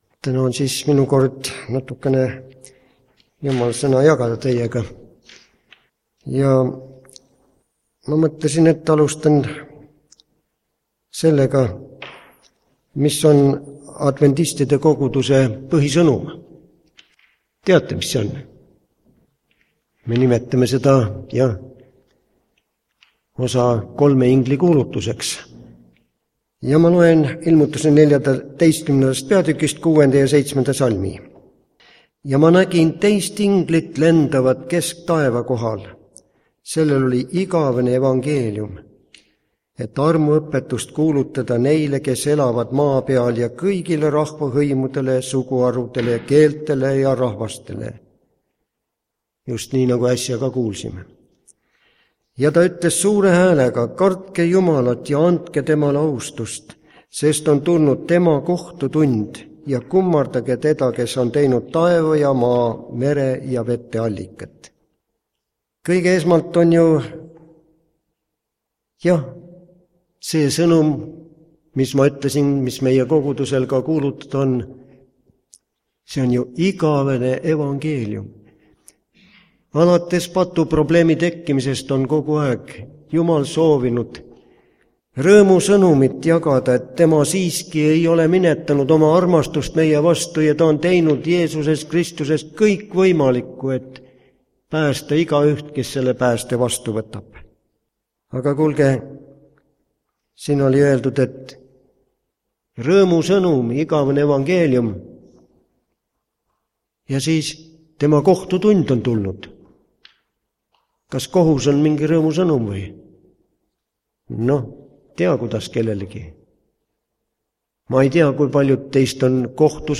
Kolme-ingli-kuulutus (Osa 1) K O H U S Muusikahetkeks üks vana laul.
Jutlused